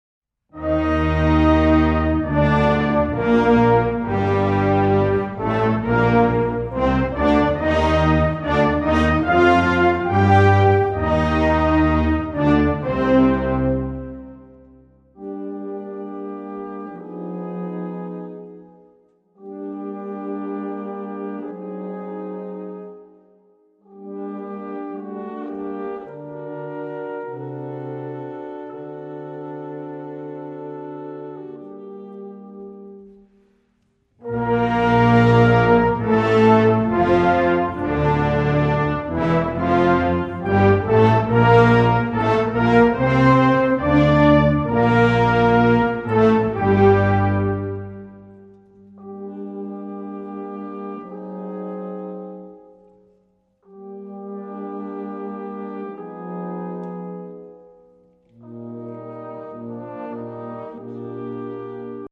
met een hoofdrol voor de alt saxofoon